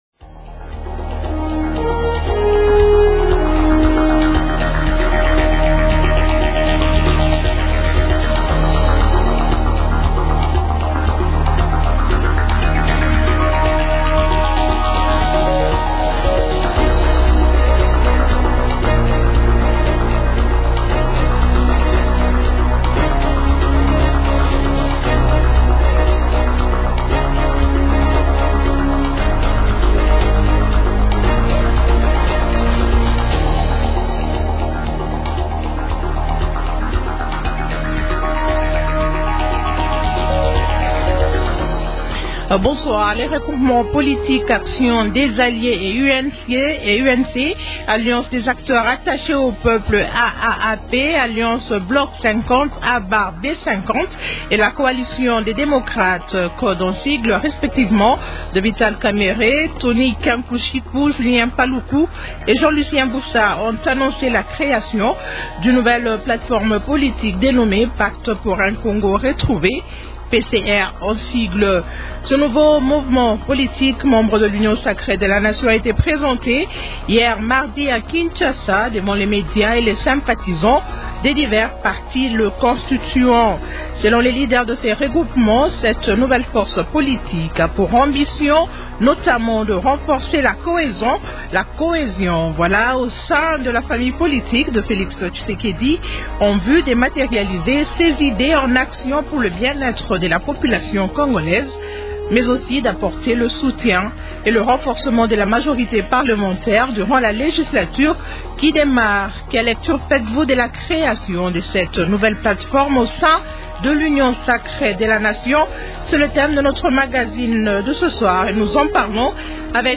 Question : -Quelle lecture faites-vous de la création de cette nouvelle plate-forme au sein de l’Union sacrée de la nation ? Invités : -Julien Paluku, ministre de l’Industrie.
- Jean Claude Tshilumbayi, député national et cadre de l’UDPS, parti membre de l’Union sacrée.